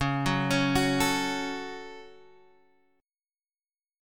Dbsus4#5 Chord